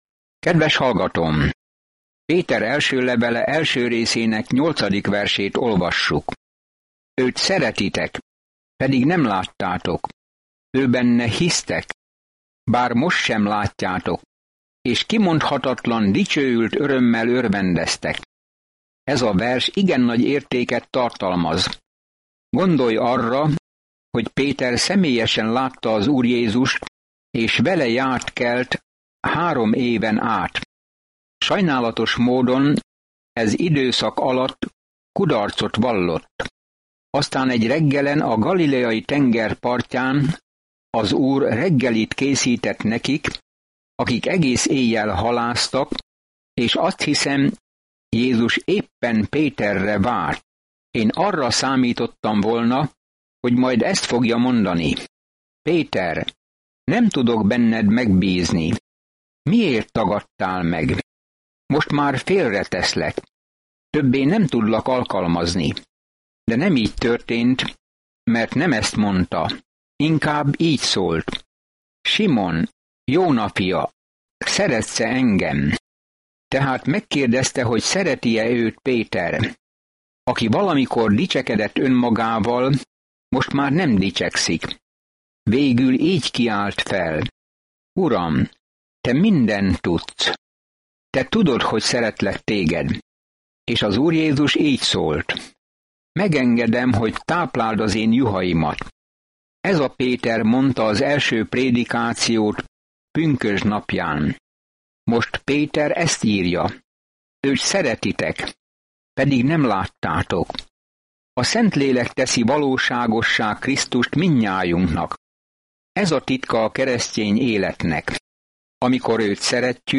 Szentírás 1Péter 1:8-16 Nap 3 Olvasóterv elkezdése Nap 5 A tervről Ha Jézusért szenvedsz, akkor ez az első levél Pétertől arra biztat, hogy Jézus nyomdokaiba lépsz, aki először szenvedett értünk. Napi utazás 1 Péteren keresztül, miközben hallgatod a hangos tanulmányt, és olvasol válogatott verseket Isten szavából.